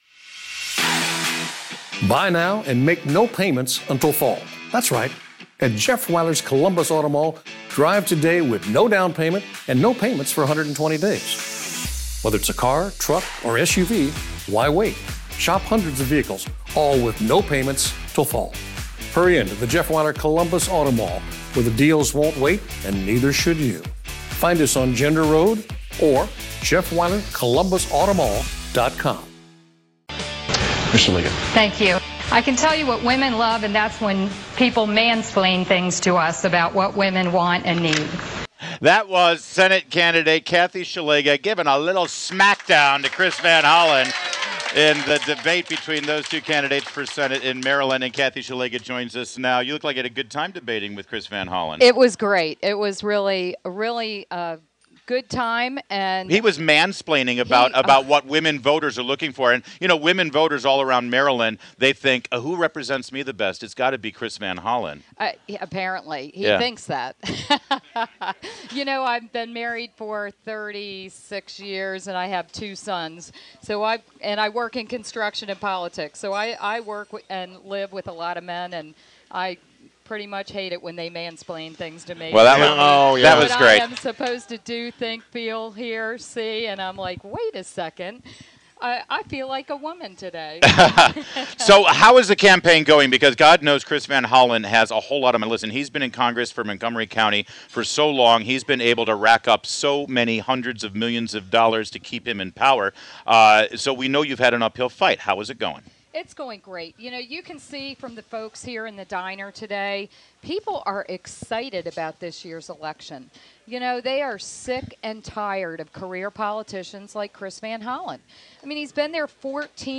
INTERVIEW — KATHY SZELIGA — (SH-LEG-GAH) — the minority whip of the House of Delegates and Republican nominee for U.S. Senate